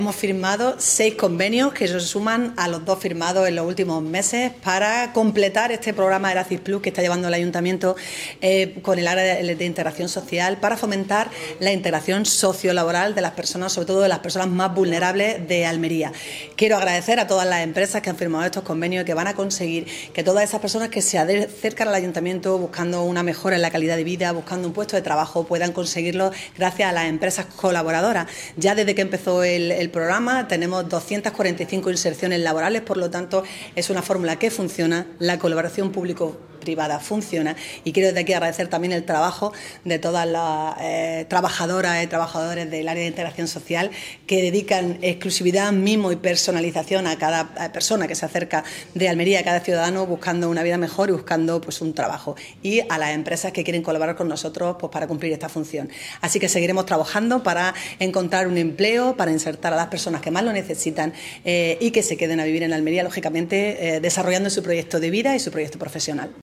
CORTE-ALCALDESA-CONVENIOS-ERACIS.mp3